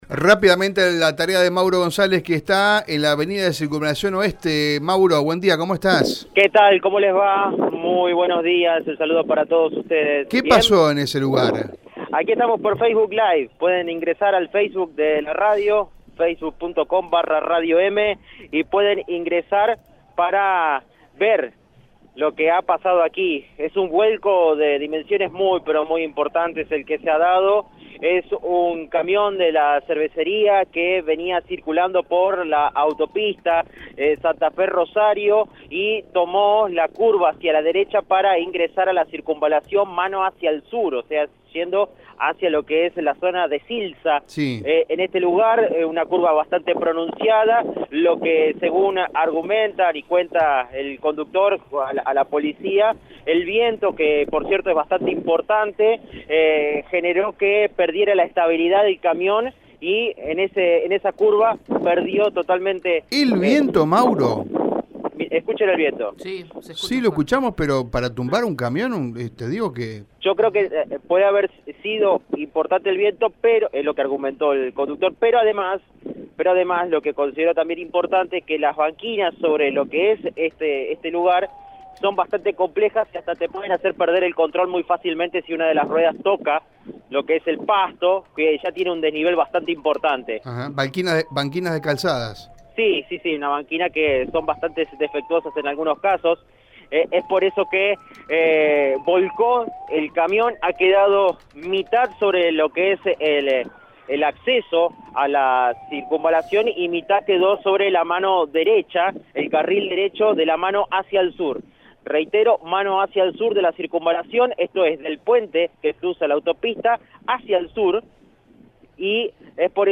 Según el móvil de Radio EME, un camión que transportaba una gran cantidad de cervezas perdió el control y volcó sobre el corredor vial.